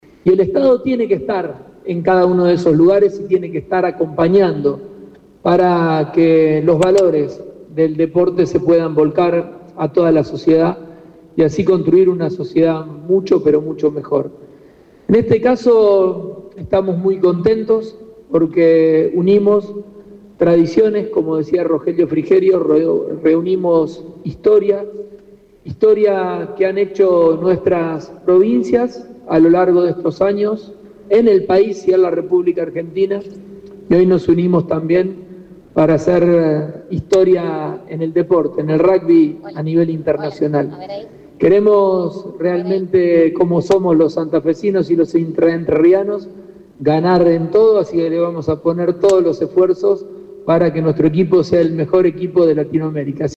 Este lunes, en la ciudad de Santa Fe, se presentó oficialmente Capibaras XV, la nueva franquicia profesional que representará al Litoral argentino en el Súper Rugby Américas a partir de 2026.
Audio del gobernador de Santa Fe, Maximiliano Pullaro: